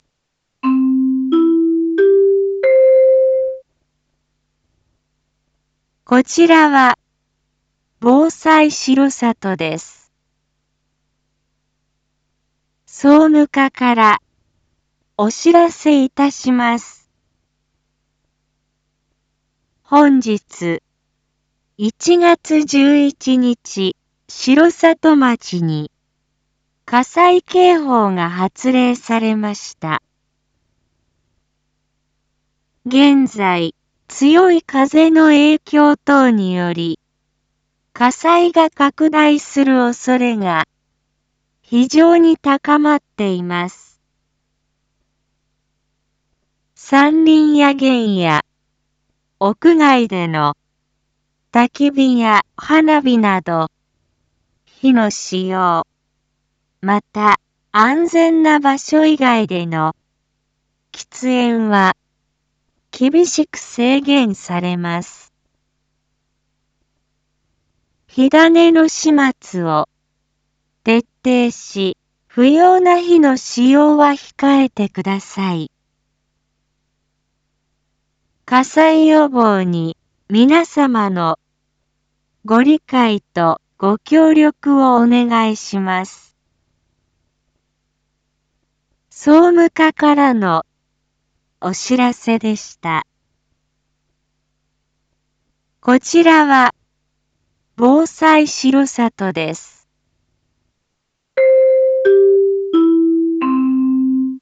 一般放送情報
Back Home 一般放送情報 音声放送 再生 一般放送情報 登録日時：2026-01-11 15:31:45 タイトル：R8.1.11火災 警報発令 インフォメーション：本日、1月11日、城里町に火災警報が発令されました。